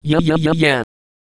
Worms speechbanks
amazing.wav